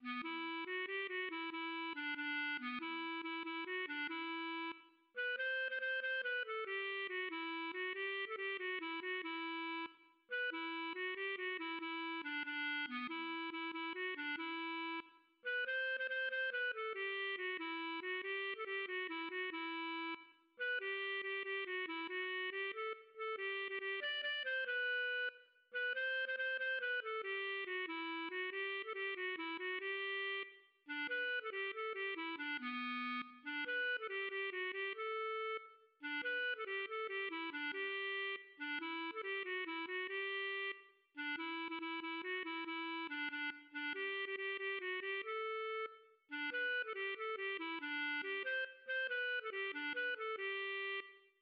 LilyPond 🏰" } myMusic = { << \chords { \germanChords \set chordChanges=##t \set Staff.midiInstrument="acoustic guitar (nylon)" | | | | } \relative c' { \time 4/4 \key g \major \time 6/8 \tempo 4=140 \partial 8 \set Staff.midiInstrument="clarinet" b8 \repeat volta 2 { e4 fis8 g8 fis8 e8